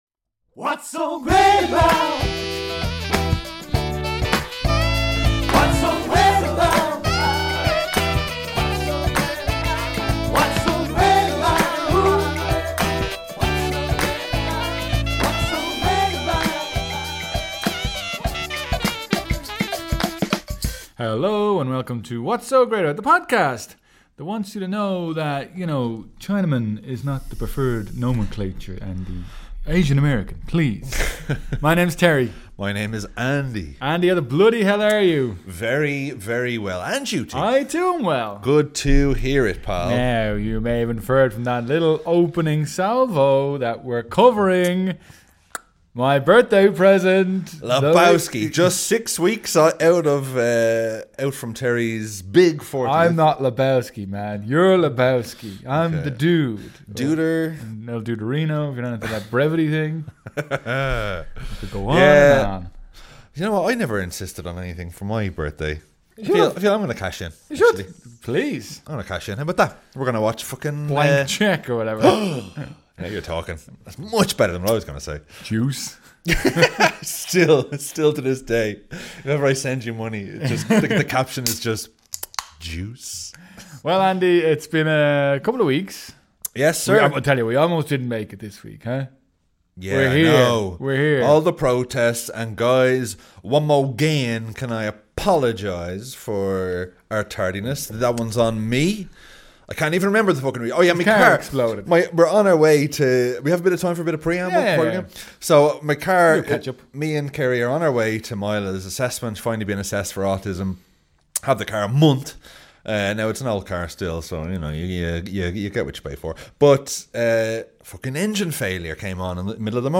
We're back in the studio